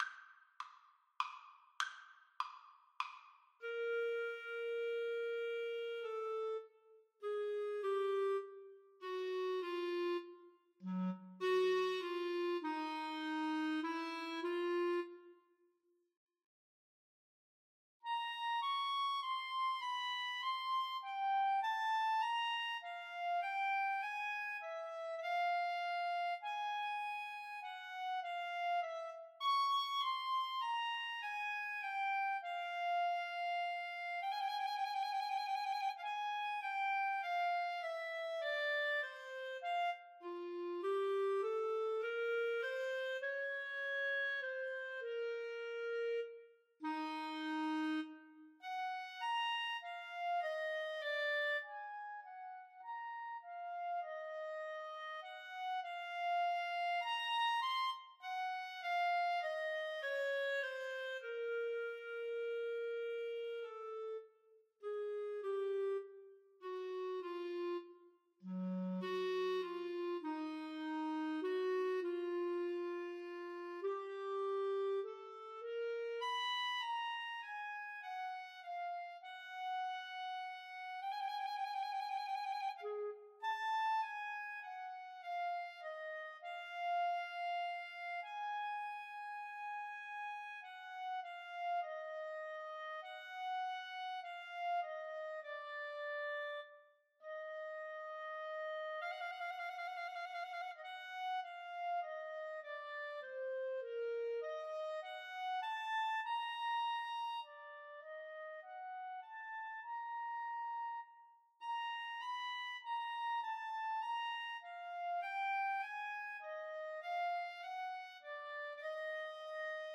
3/4 (View more 3/4 Music)
Lamento
Clarinet Duet  (View more Intermediate Clarinet Duet Music)
Classical (View more Classical Clarinet Duet Music)